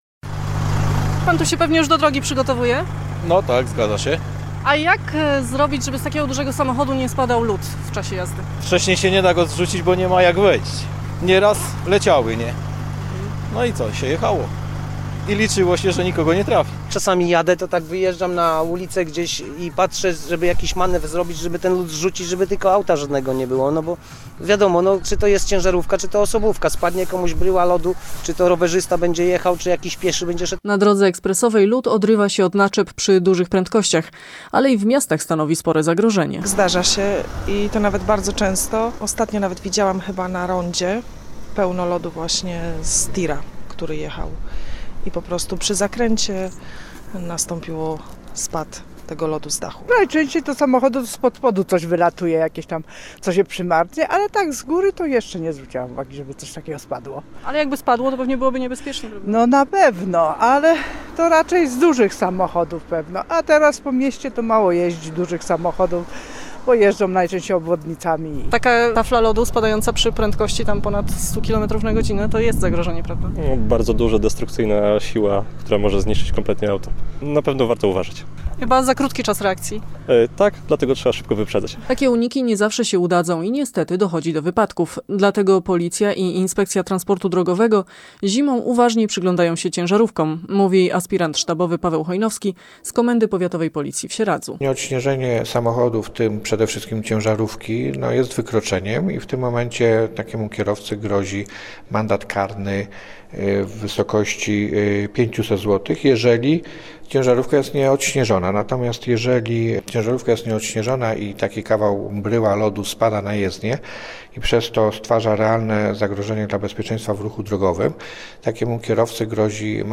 – Nie mam uprawnień do prac na wysokościach a naczepa ma 4 metry – tłumaczy jeden z kierowców ciężarówki w czasie postoju na MOP-ie Paprotnia, przy trasie S8.